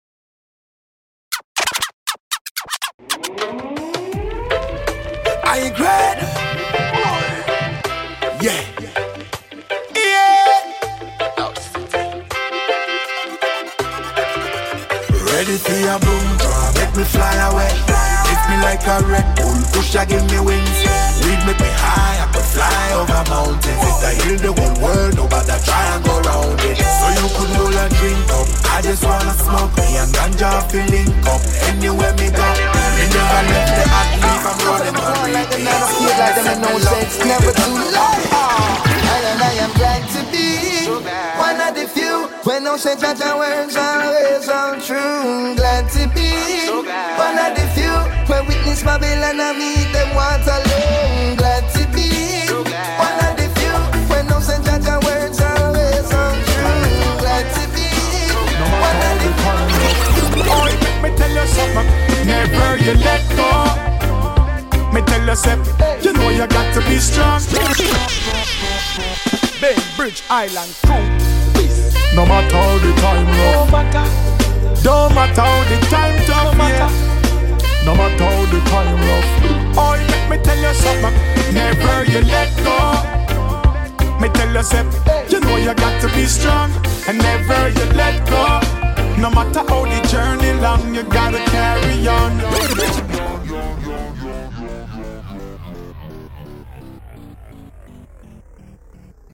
exclusive mix